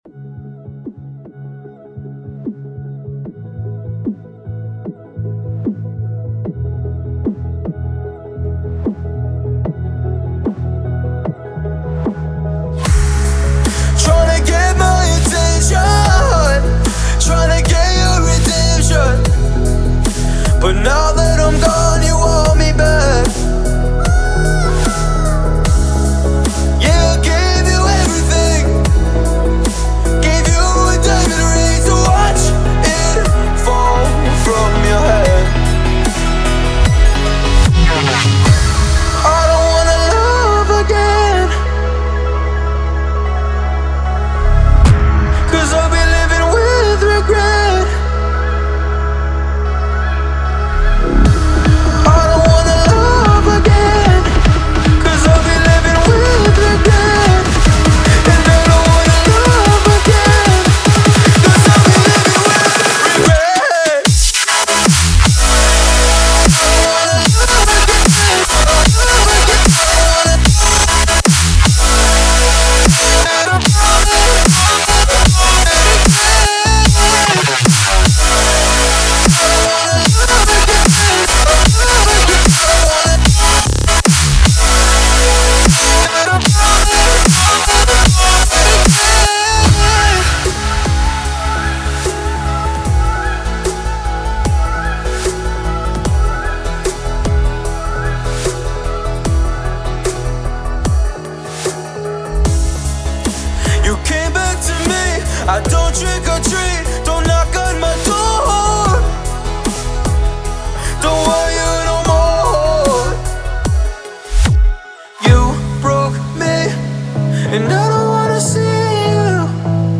Melodic dubstep, Bass music, Sneaking, Heavy, Suspense & Sad